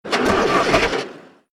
KART_Engine_start_0.ogg